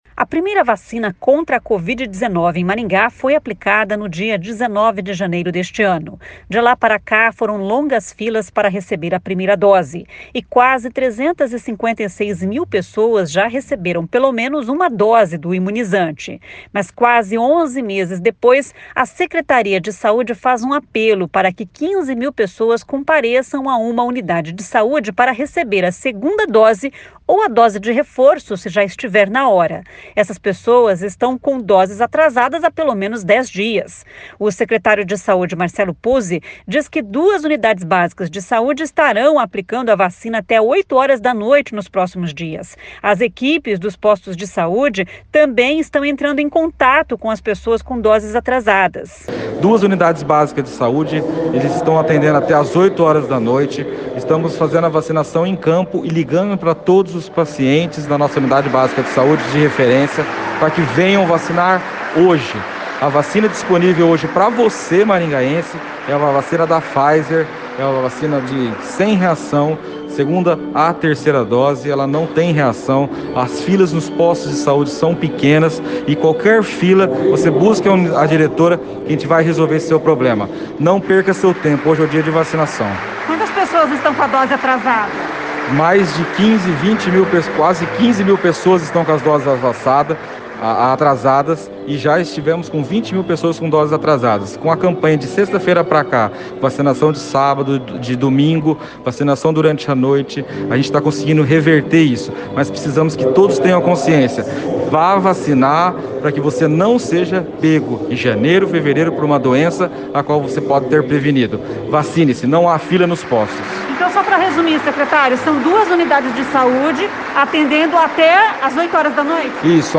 O secretário de Saúde Marcelo Puzzi diz que duas UBSs estarão aplicando a vacina até oito horas da noite nos próximos dias.